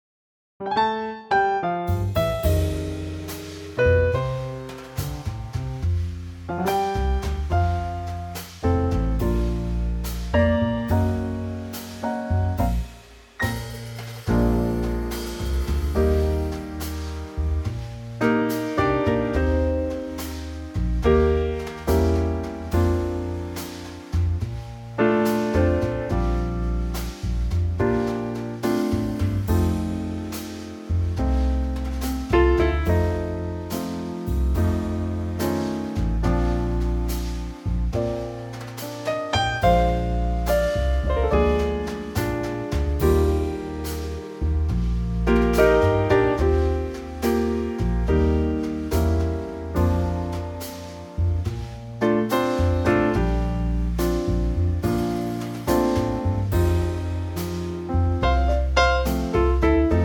key - Bb - vocal range - Bb to Bb
-Unique Backing Track Downloads